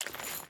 Water Chain Walk 1.wav